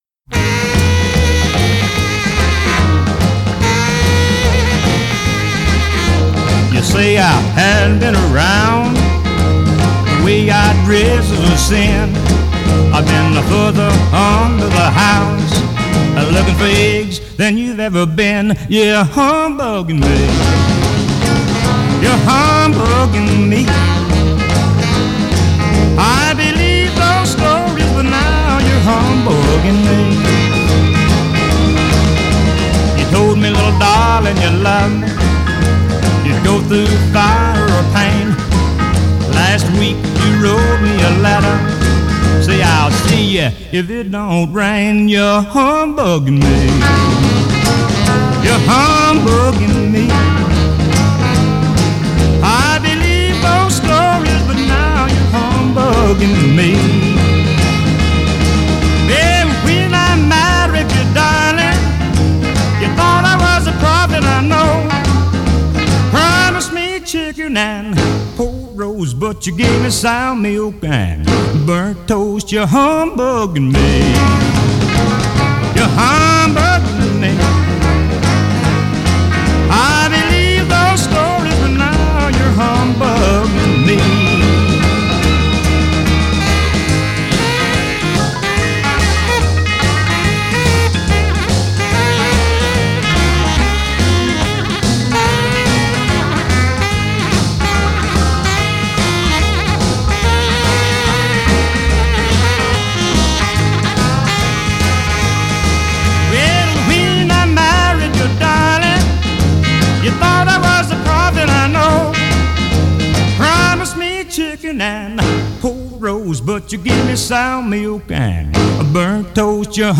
was an outstanding country musician